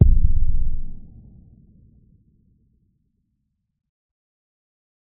闷响的远处爆炸声
描述：这实际上是一个低音调的原木鼓声，带有延迟混响，在远处一声巨响后听到的那种自然的声音反射。
标签： 咚咚 冲击 轰隆 引爆 拆除 爆炸 炸药 战斗 破坏 打击 巨大 爆炸 武器 沉闷 强大 有力 战争 炸弹 巨大
声道立体声